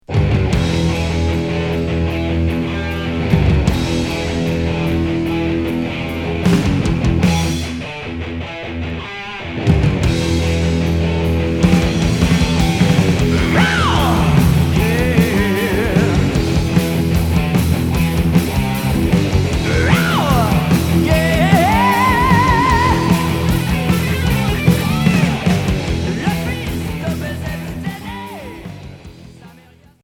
Hard heavy